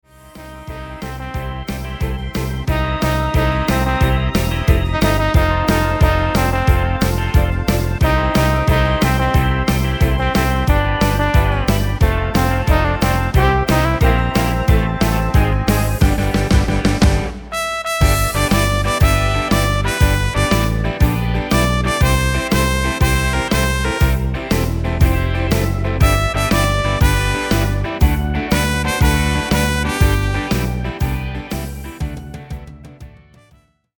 Dette medley indholder f�lgende titler:
Instrument Tyros5